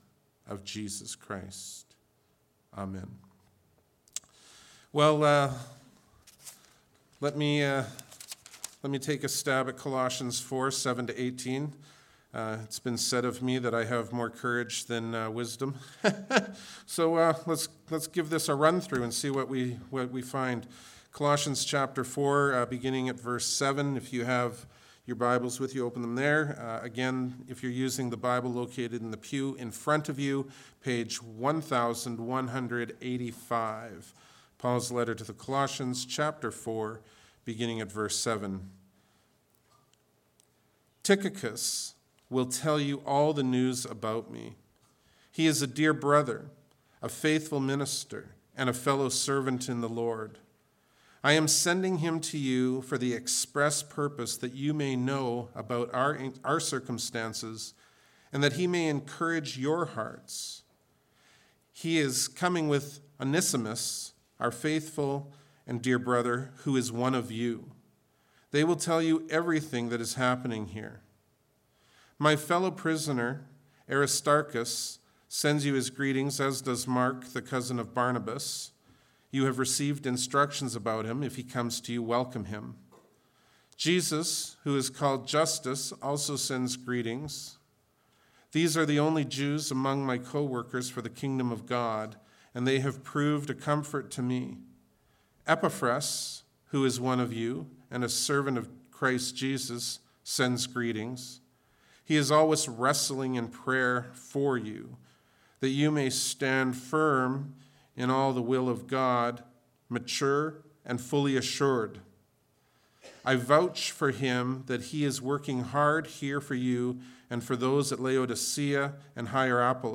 Bible Text: Philippians 2:5-11 | Preacher